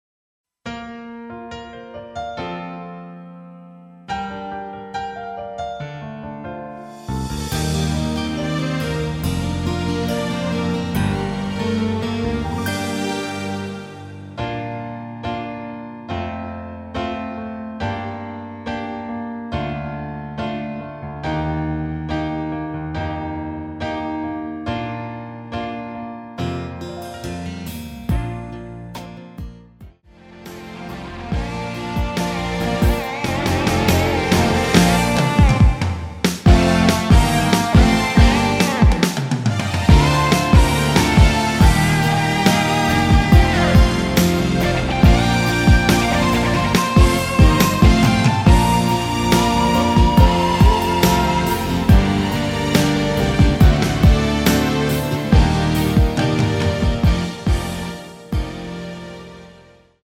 Bb
앞부분30초, 뒷부분30초씩 편집해서 올려 드리고 있습니다.
중간에 음이 끈어지고 다시 나오는 이유는
위처럼 미리듣기를 만들어서 그렇습니다.